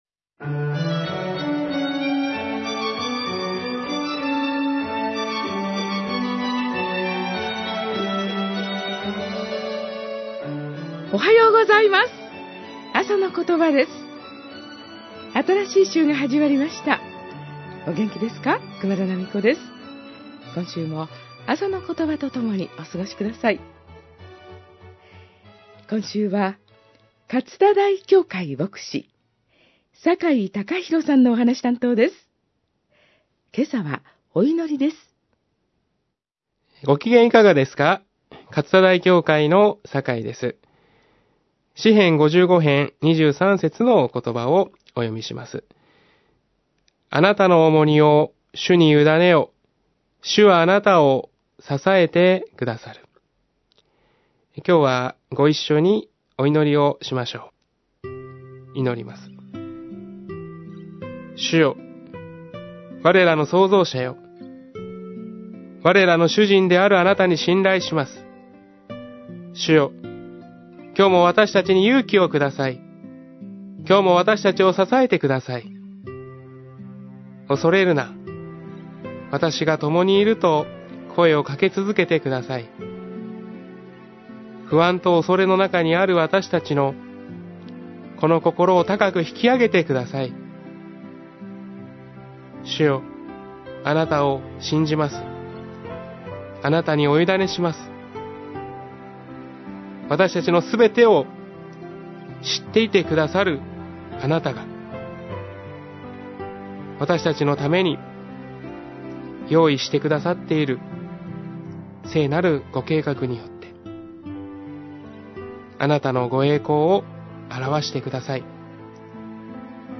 あさのことば 2013年11月17日（日）放送
メッセージ： 祈り（詩編55:23）